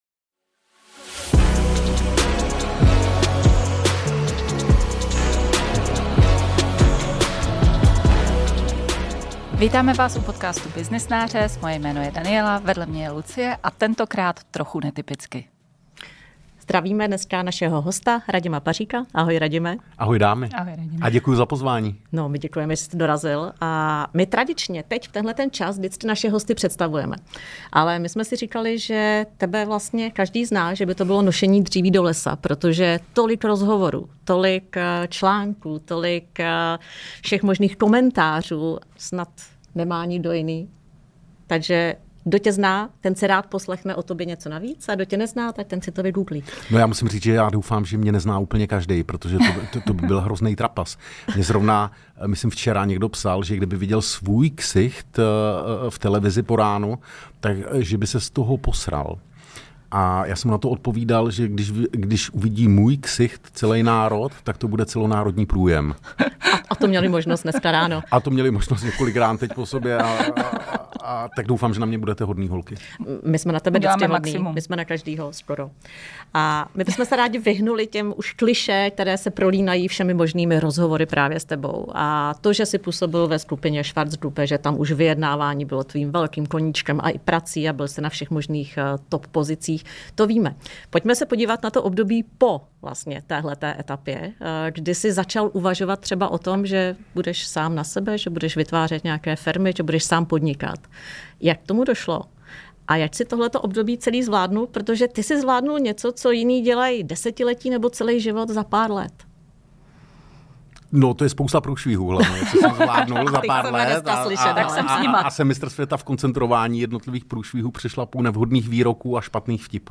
Nebyli jsme ale zdaleka jenom vážní, rozhovor jsme zdatně odlehčovali spoustou smíchu.